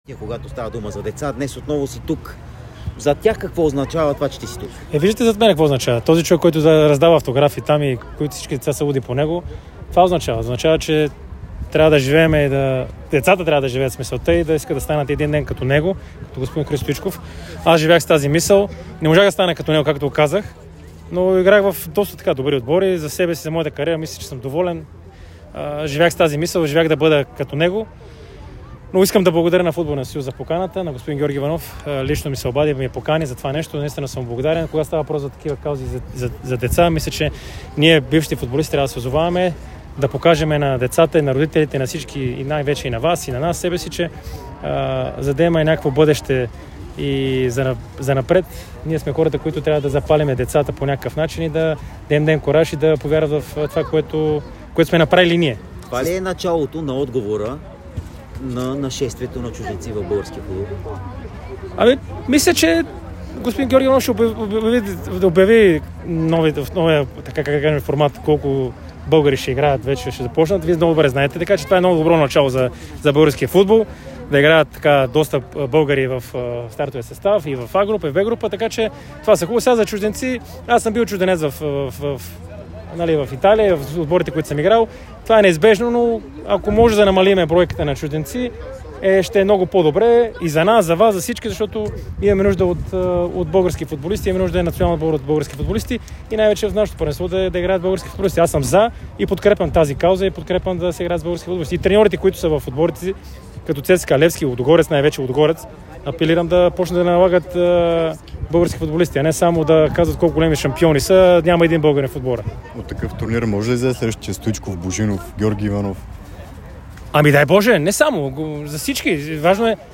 Валери Божинов бе сред участниците в награждаването във финалния турнир „Училищни лъвчета“. Той сподели, че е живял с мисълта да бъде като Христо Стоичков.